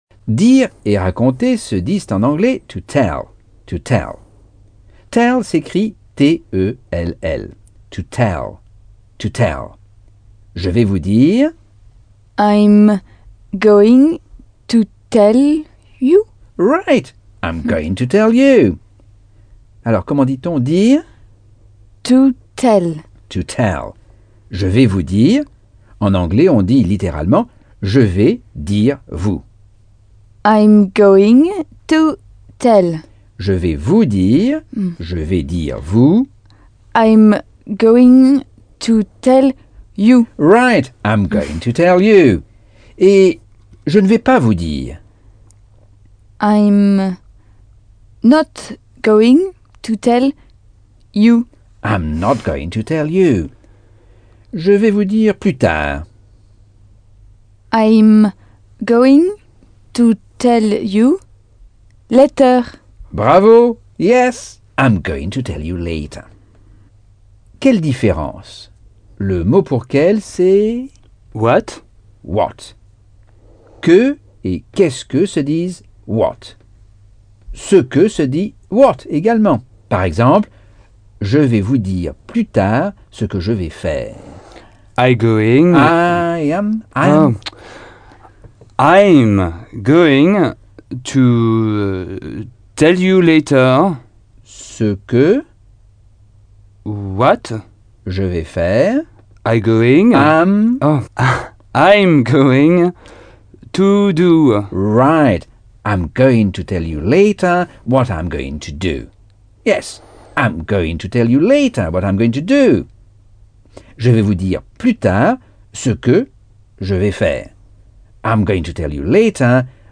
Leçon 4 - Cours audio Anglais par Michel Thomas